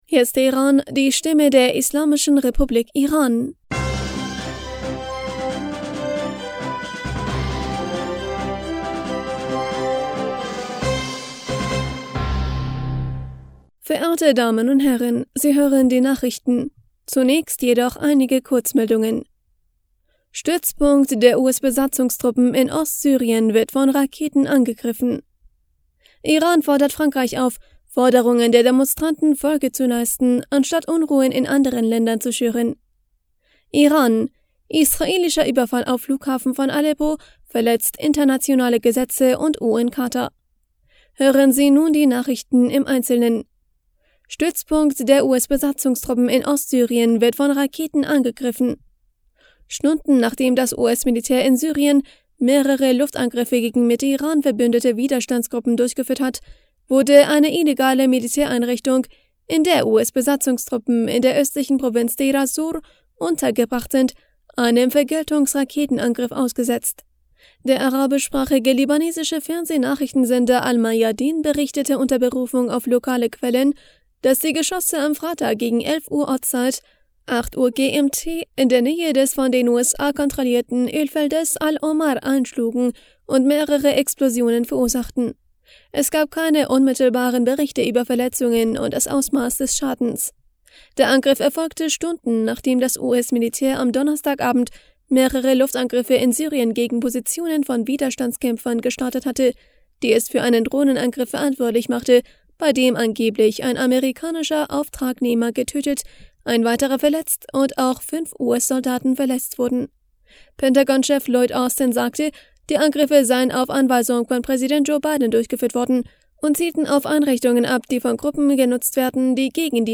Nachrichten vom 24. März 2023